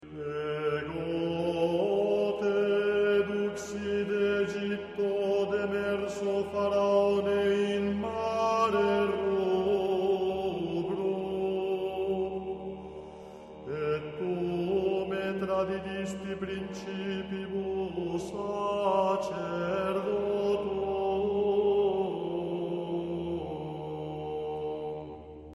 Esecuzione sulla scorta di codici del rito romano antico.
La seconda parte ribadisce gli stessi concetti sopra espressi ma suddivisi in nove improperia (cantati dai soli su modello salmodico) intercalati dal coro che ripete ogni volta i primi versi con cui aveva esordito nella prima parte: "Popule meus, quid feci tibi? Aut in quo contristavi te? Responde mihi!":